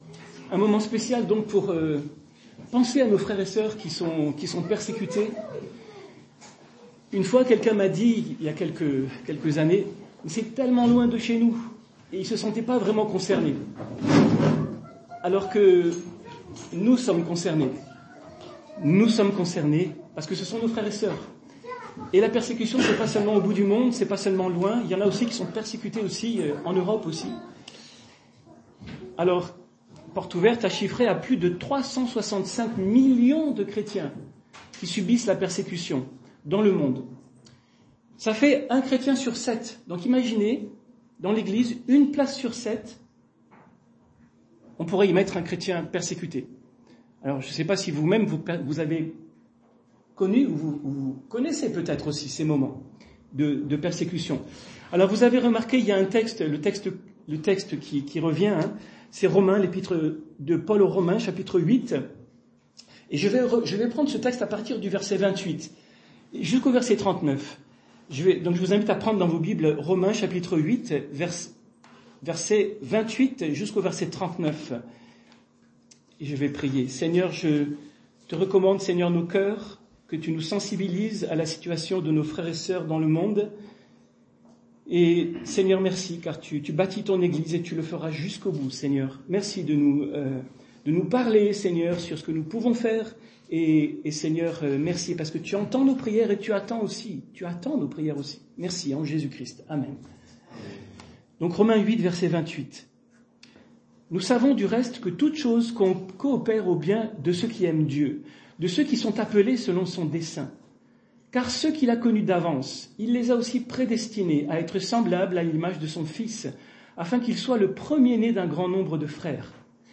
Culte de l'Eglise Persécuté - 24 novembre 2024 - EPEF